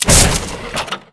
1 channel
Flare.wav